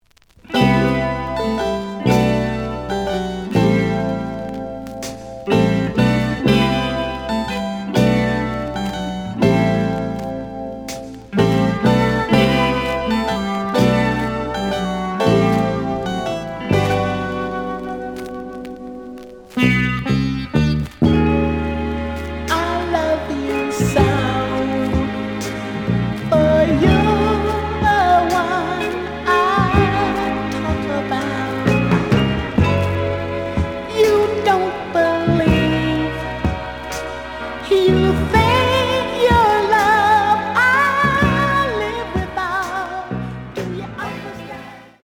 The audio sample is recorded from the actual item.
●Genre: Soul, 70's Soul
Some noise on first half of B side.)